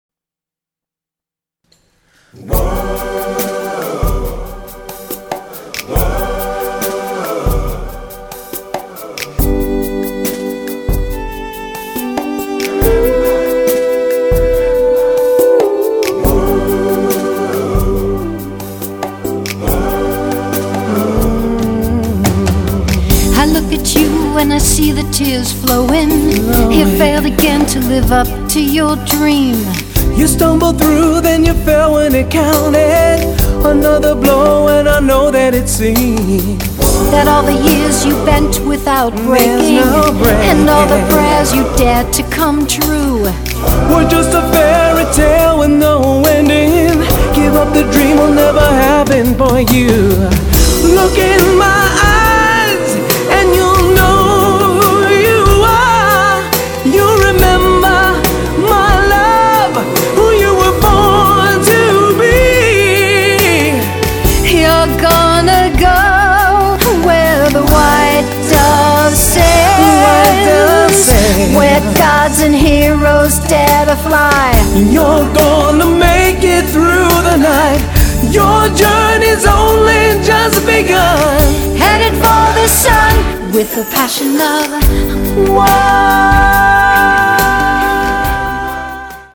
Free Audio Book Wise Women Get What They Want